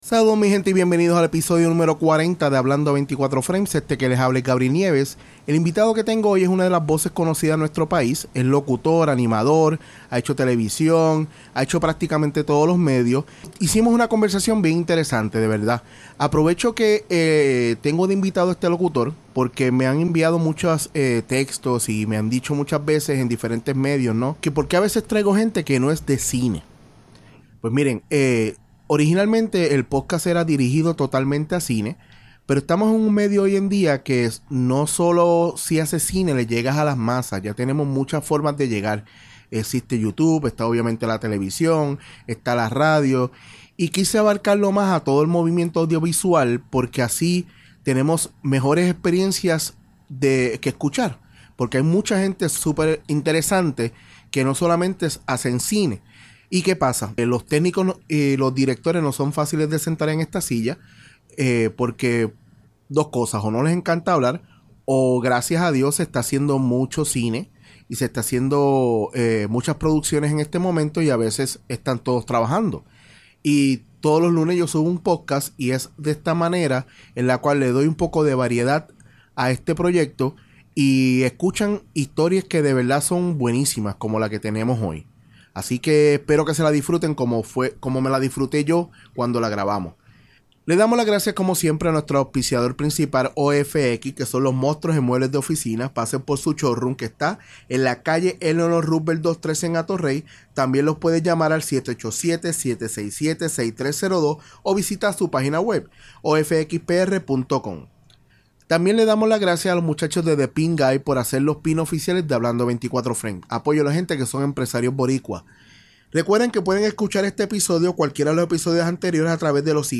En el episodio # 40 hablo con una de las voces más reconocidas de este país y uno de los fajones de la industria prácticamente a hecho de todo.